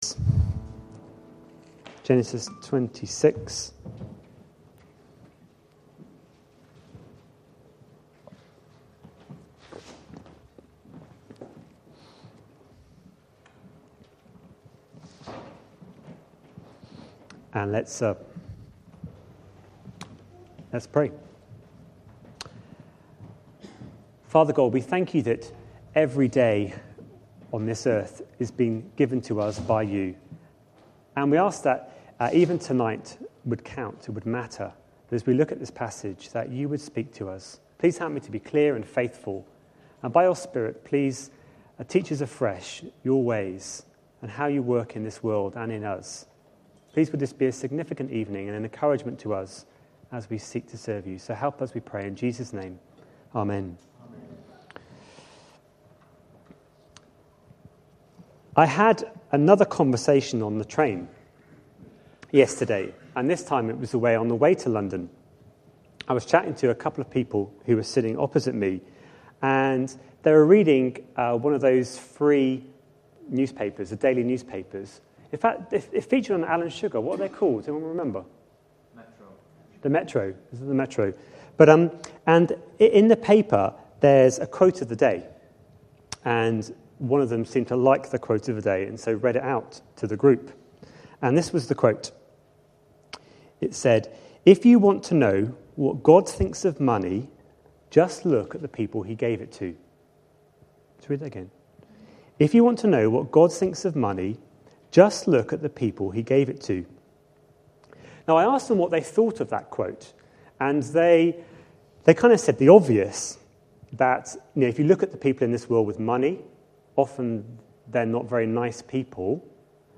A sermon preached on 19th June, 2011.